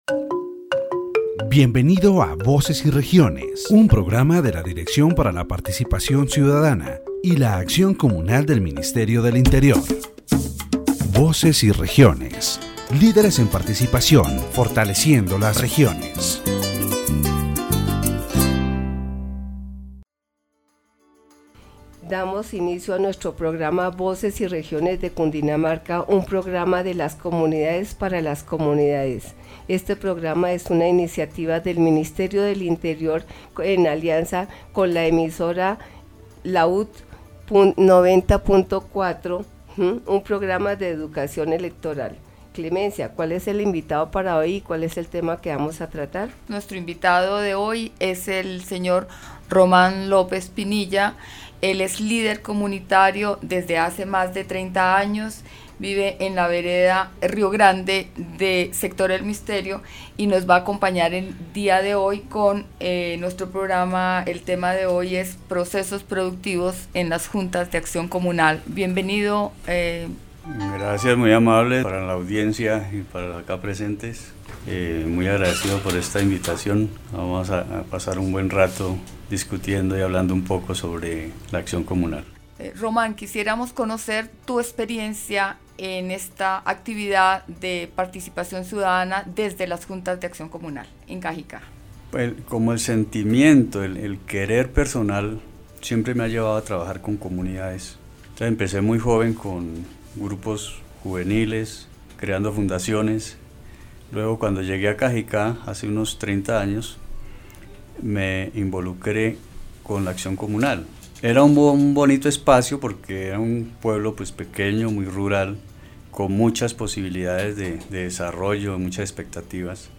In this section of the Voces y Regiones program, the interviewee discusses the history of the Community Action Boards (JAC) and how, in some cases, politicians took advantage of these organized groups to gain electoral support, which led to the community losing its voice and representation.